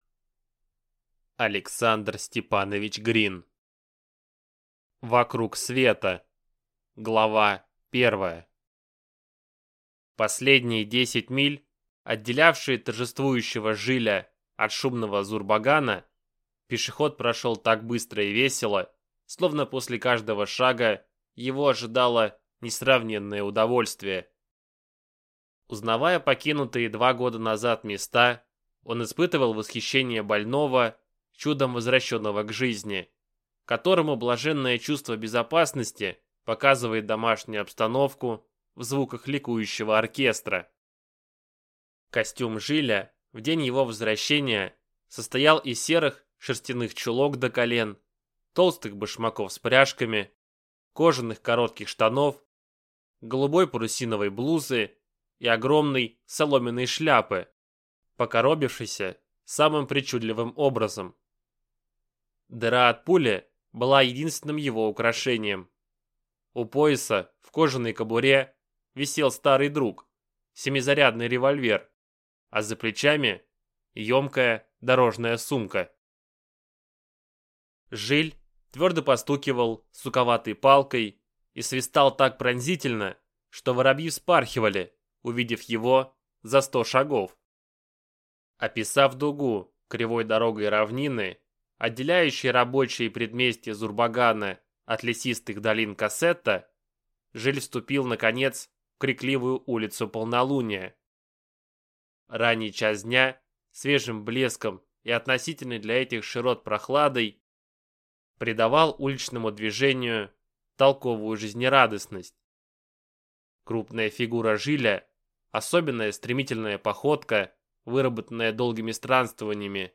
Аудиокнига Вокруг света | Библиотека аудиокниг